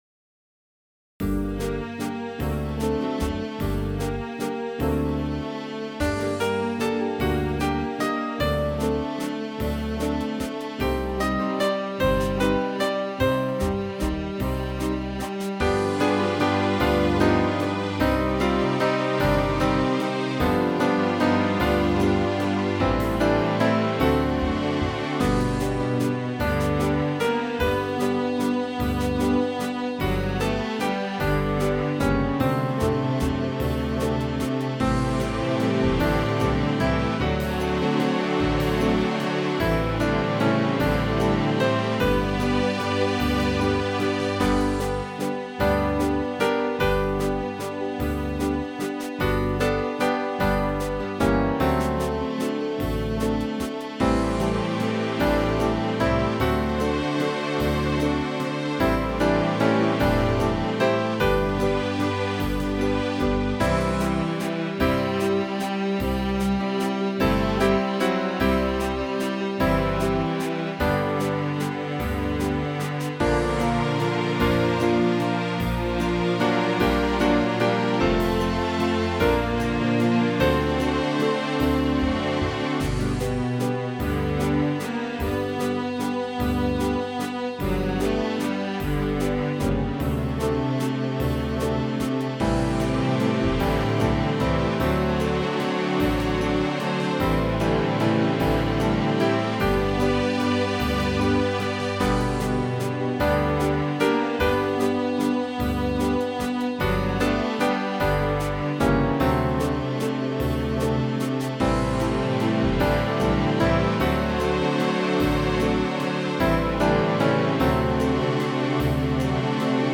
12-beat intro.
This song is in 3/4 waltz time.